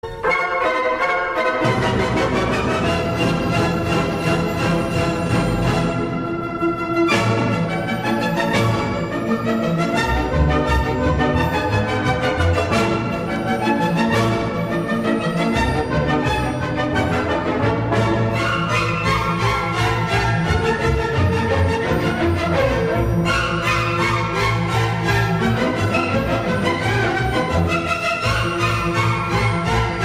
Note: The sound quality of the audio files that I am sharing below depends on multiple factors, i.e., the microphone from which it’s recorded, the quality of your speakers where you are listening to the audio, the turntable setup, and the mastering of the original audio.
Ortofon 2M Red MM Cartridge: